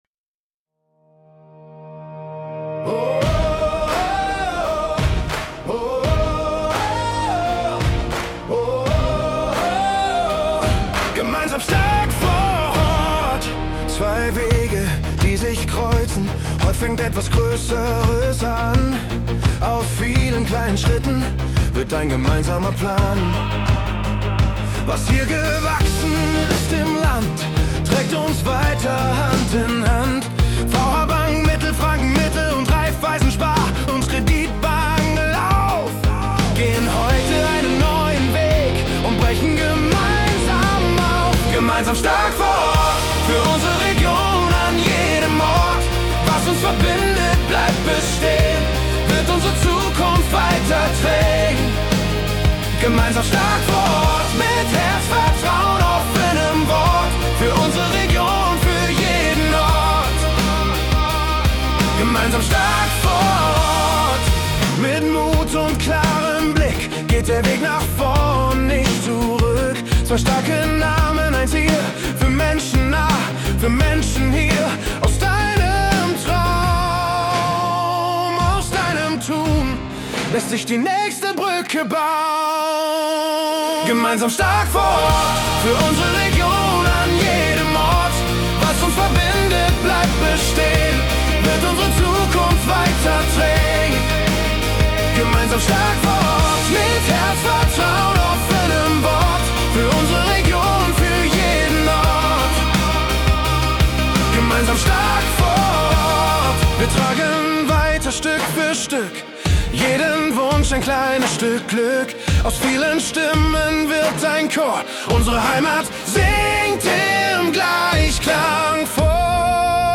* erstellt mit KI